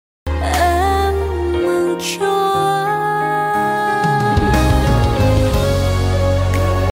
gioemmaidinhmaidinh Meme Sound Effect